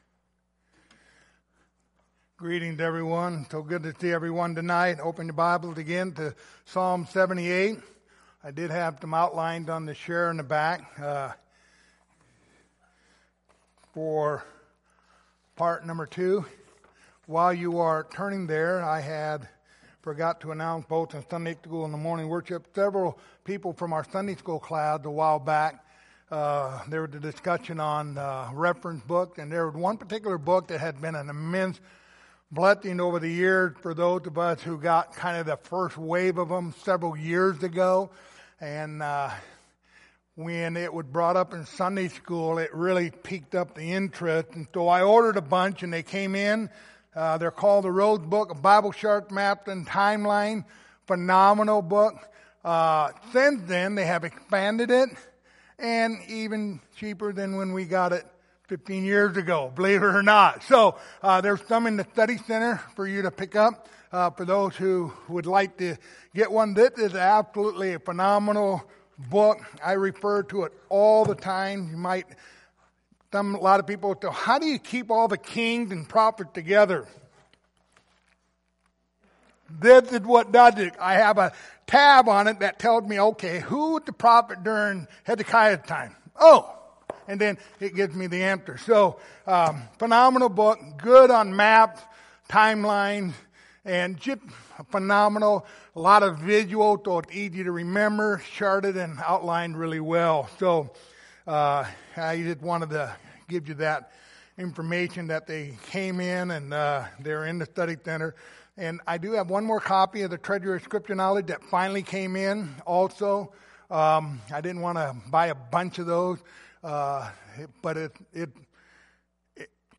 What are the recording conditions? Passage: Psalms 78:32-55 Service Type: Sunday Evening Topics